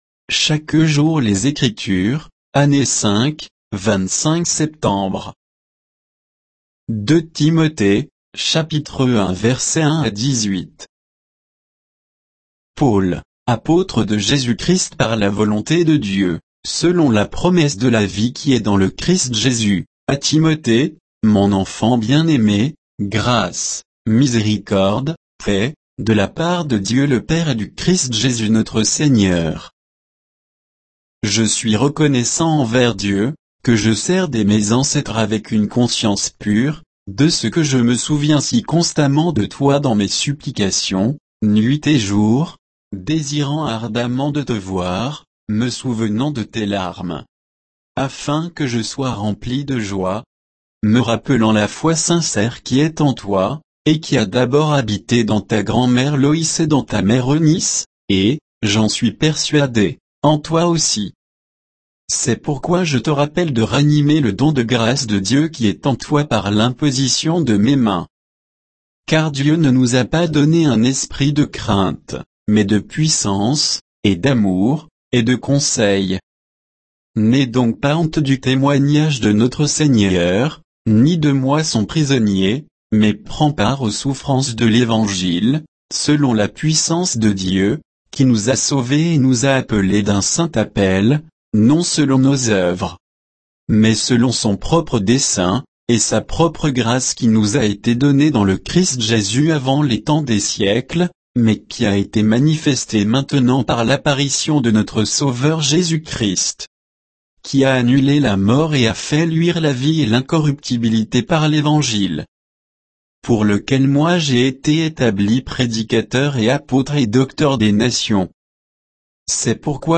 Méditation quoditienne de Chaque jour les Écritures sur 2 Timothée 1, 1 à 18